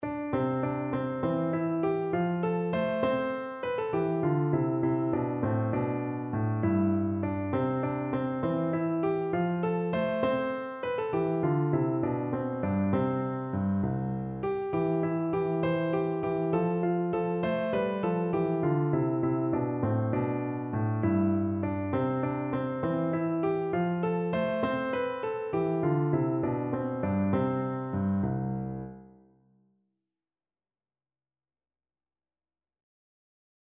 No parts available for this pieces as it is for solo piano.
6/8 (View more 6/8 Music)
Piano  (View more Easy Piano Music)
Traditional (View more Traditional Piano Music)